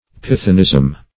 Pythonism \Pyth"o*nism\, n.